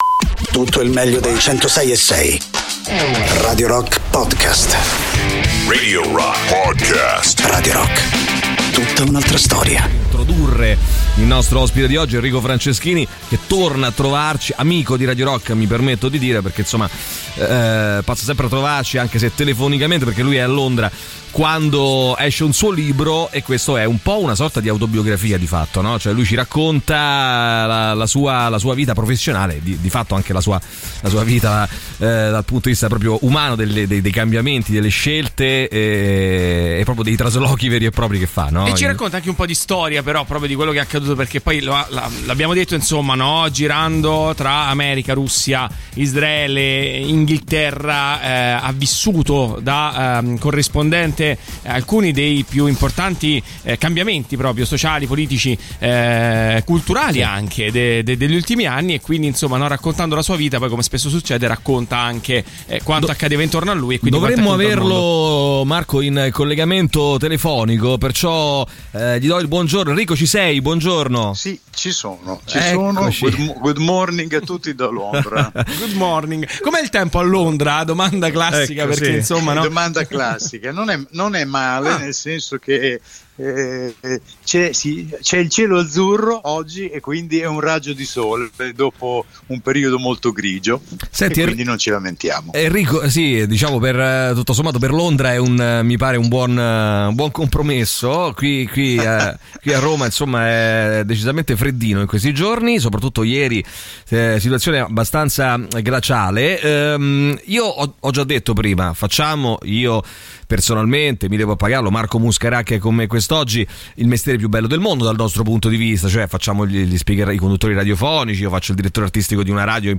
Interviste: Enrico Franceschini (01-02-23)